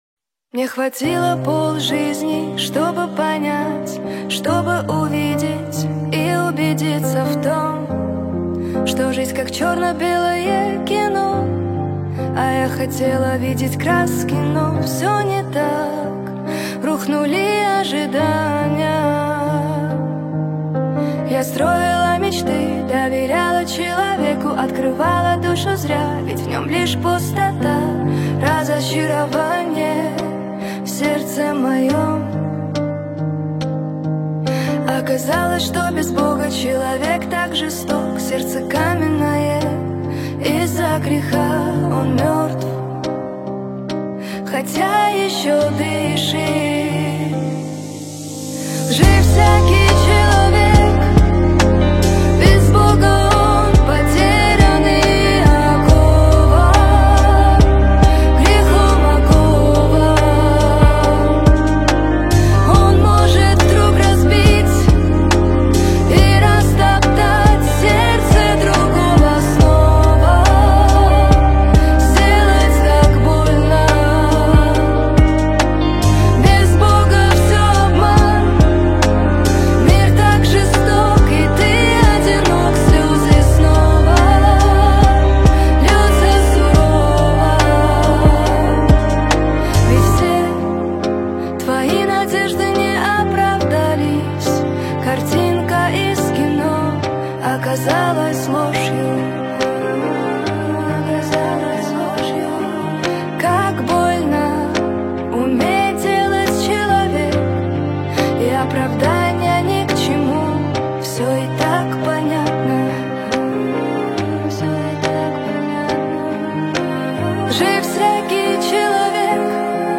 песня
6825 просмотров 5300 прослушиваний 1046 скачиваний BPM: 70